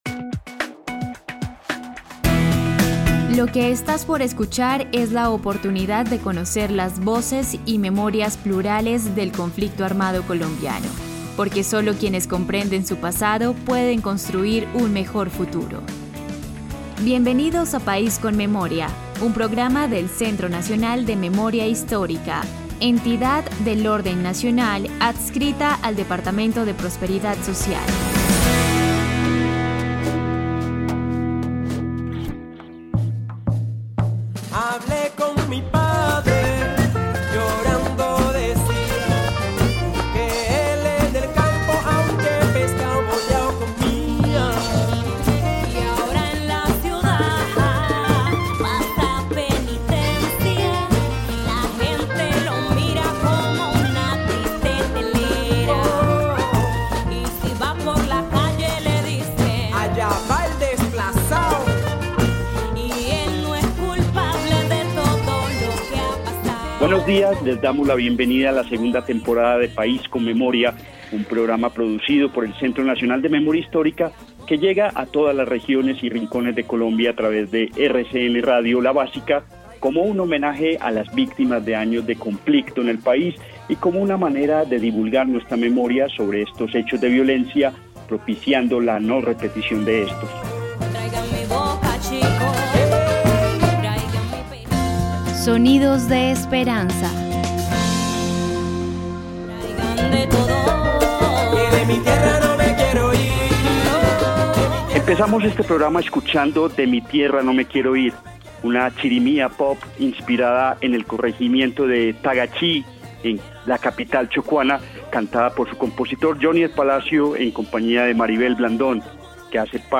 presentador.
presentadora.
cantante